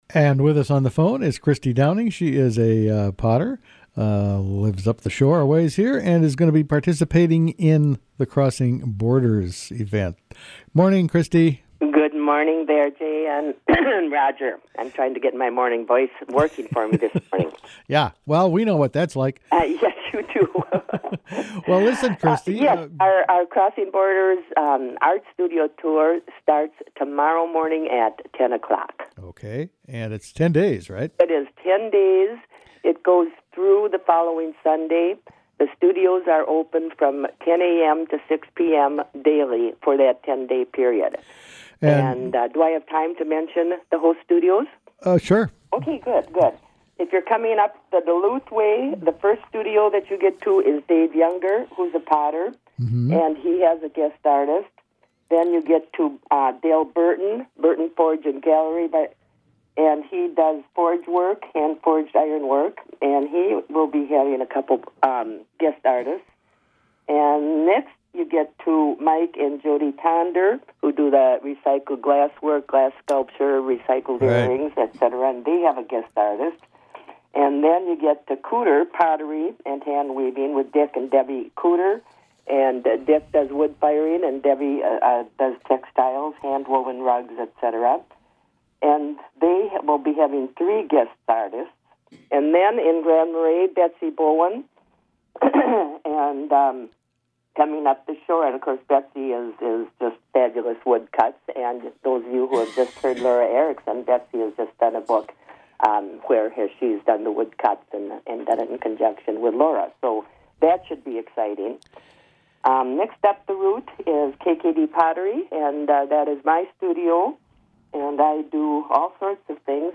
The Crossing Borders artist studio tour starts Friday, Sept. 28 and continues for 10 days. One of the participating artists is raku potter